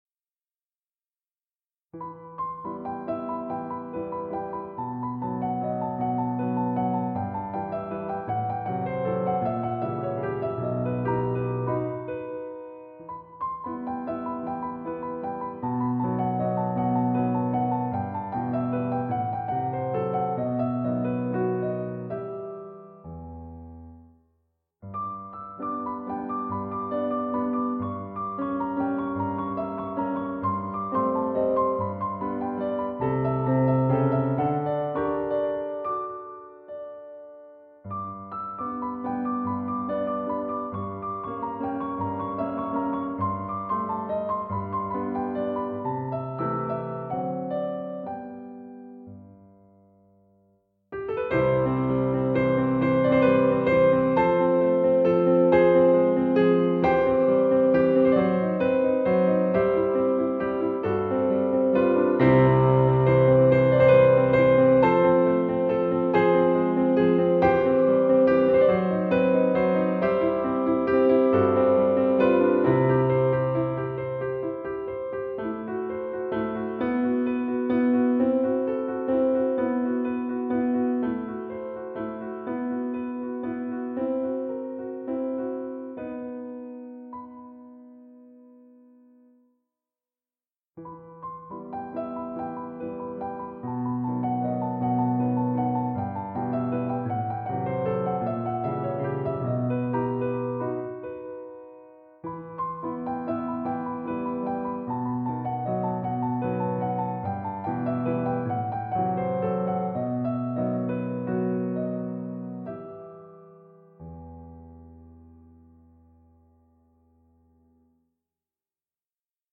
왈츠 1번